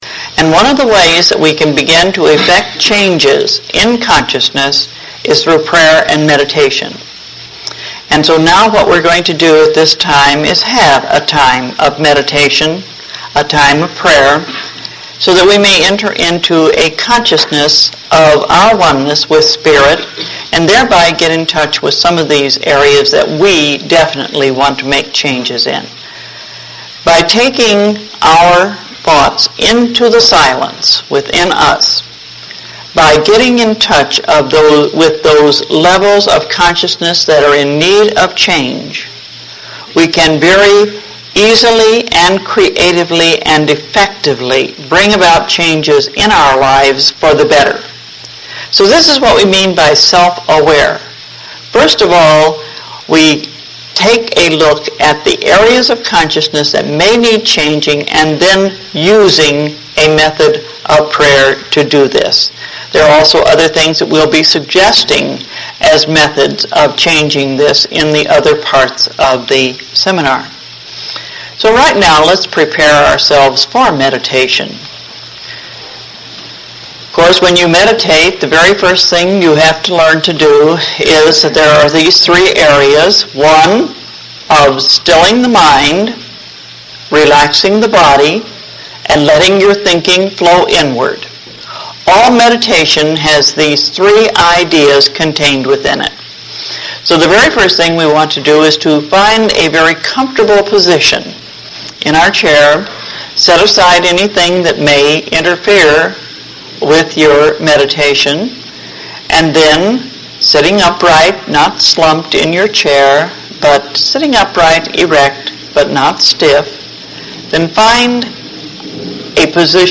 Lesson Two Meditation